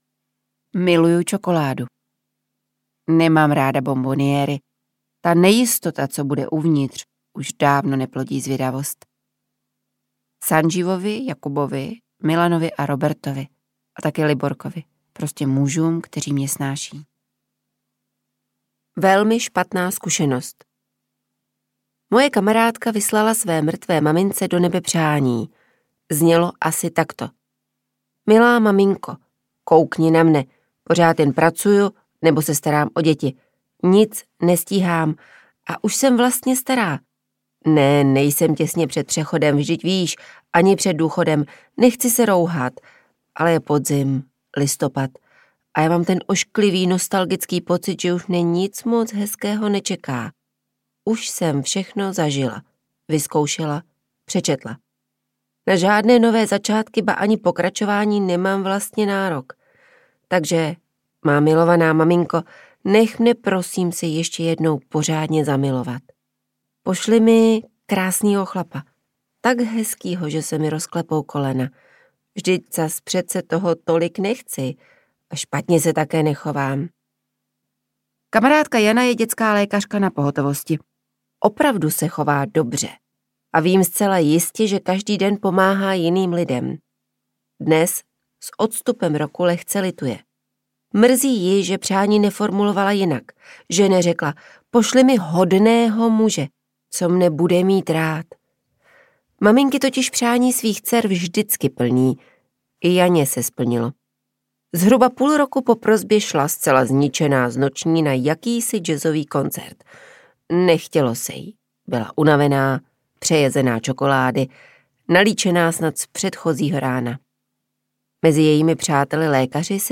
Pralinky audiokniha
Ukázka z knihy
• InterpretBarbara Nesvadbová